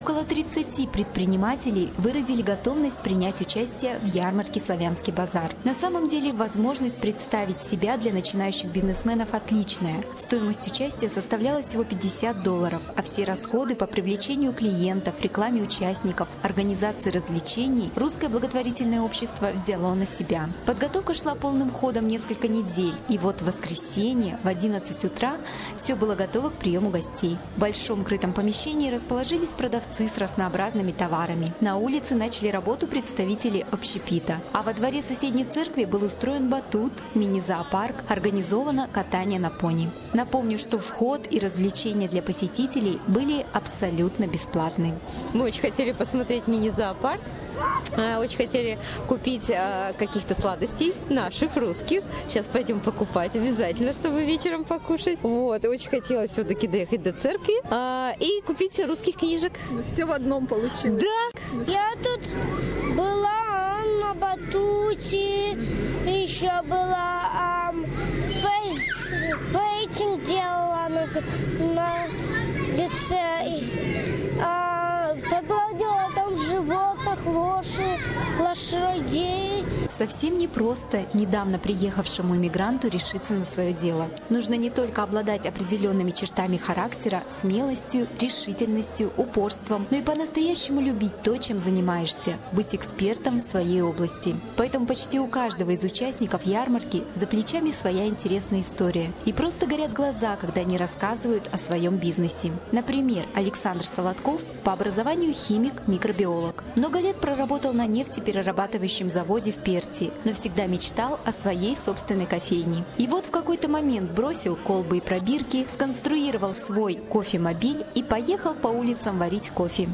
Как это было - в нашем следующем репортаже.